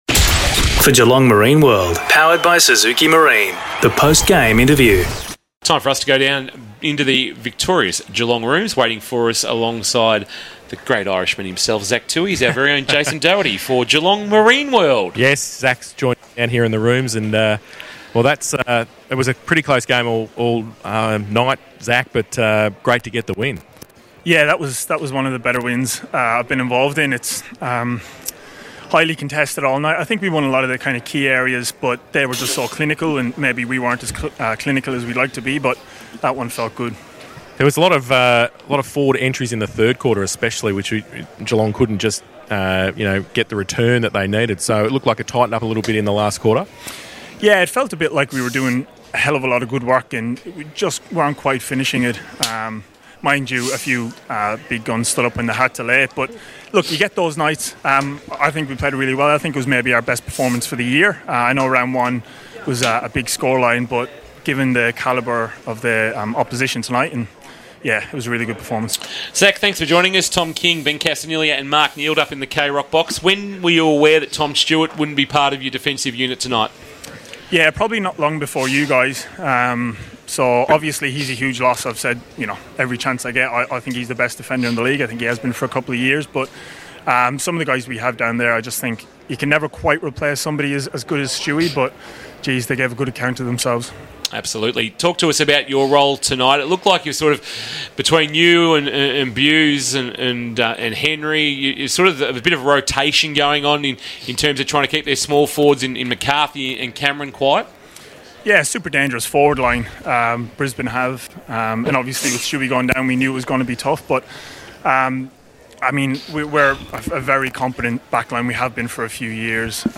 2022 - AFL - ROUND 4 - GEELONG vs. BRISBANE LIONS: Post-match Interview - Zach Tuohy (Geelong Cats)